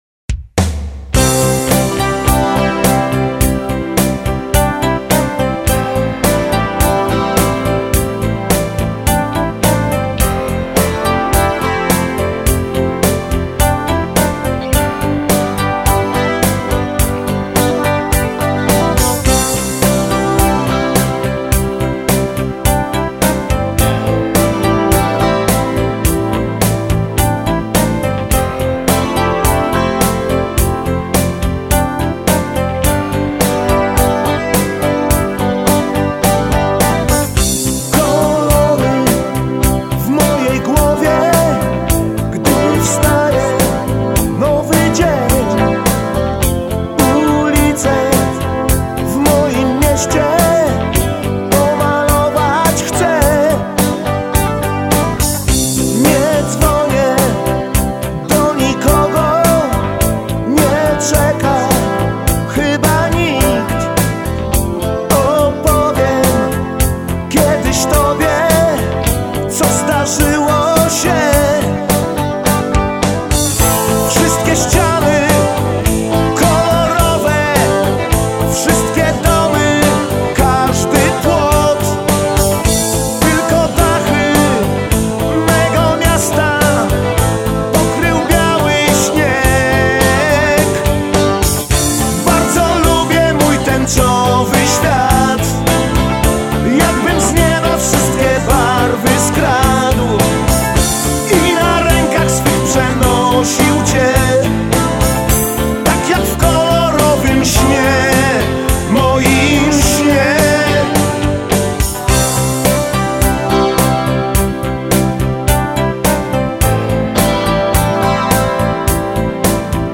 popular Polish rock band